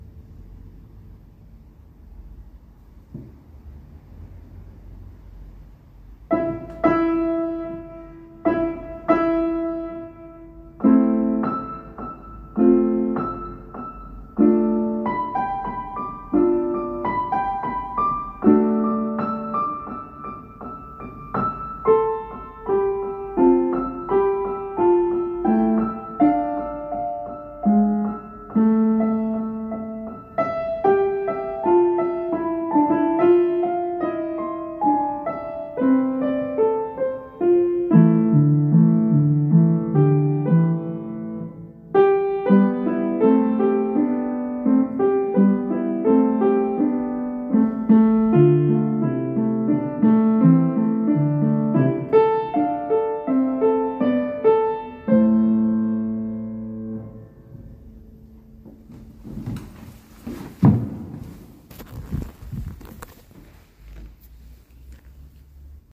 Komposition für Klavier ohne Pedal I
klavier_ohne_pedal_1.mp3